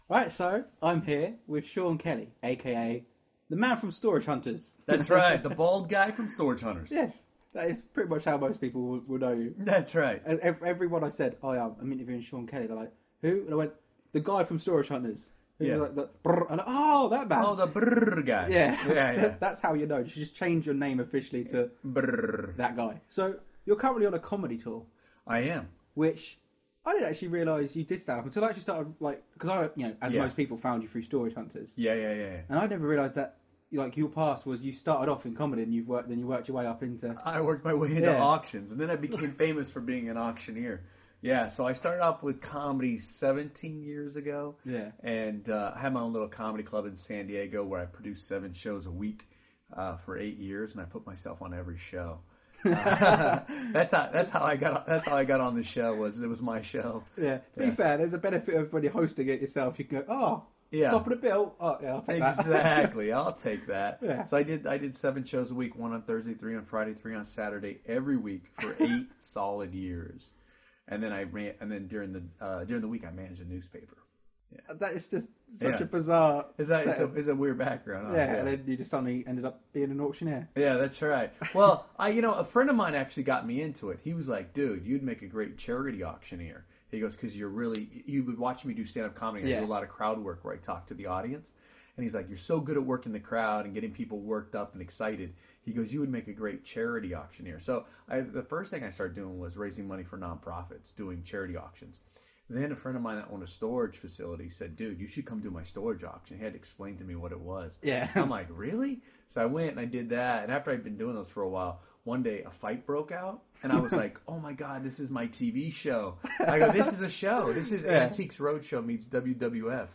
Sean Kelly from Storage Hunters Interview Oct 2015
I caught up with Sean Kelly from Storage Hunters on the 18th October just before his stand up show at The Wedgewood Rooms in Portsmouth.